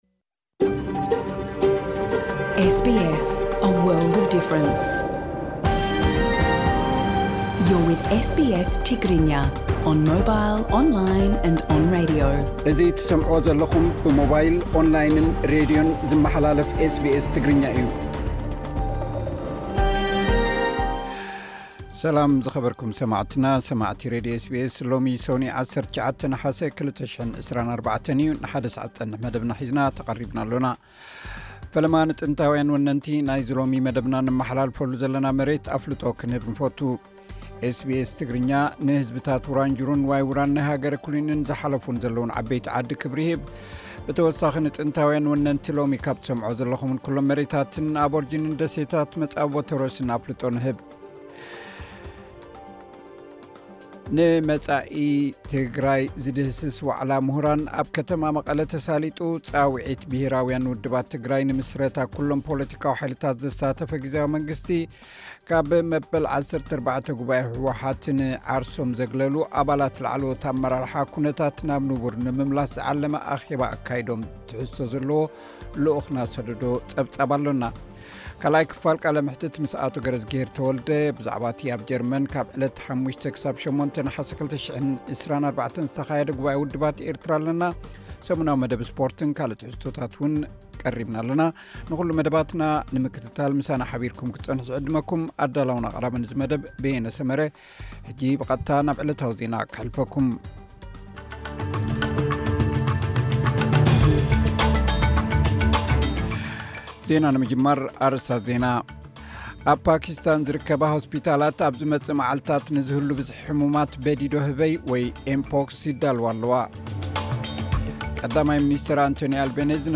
ዕለታዊ ዜና ኤስ ቢ ኤስ ትግርኛ (19 ነሓሰ 2024)